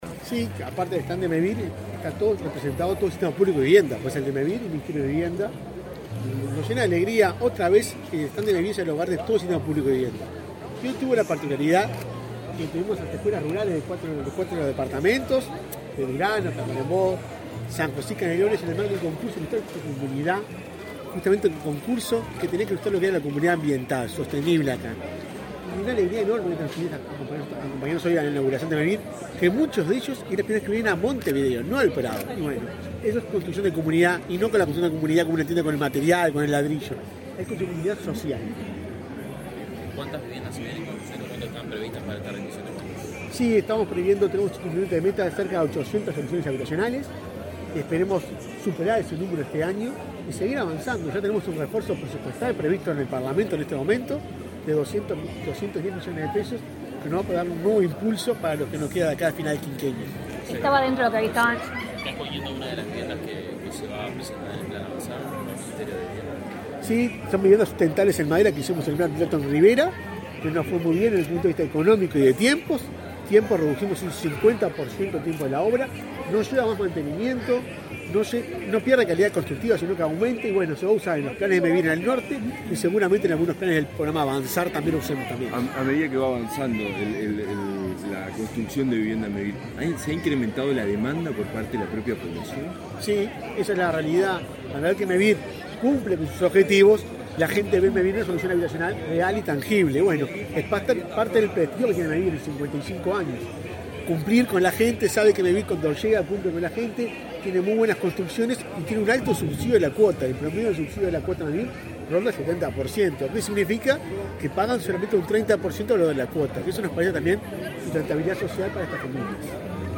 Declaraciones del presidente de Mevir a la prensa
El presidente de Mevir, Juan Pablo Delgado, dialogó con la prensa luego de inaugurar el stand de ese organismo en la Expo Prado.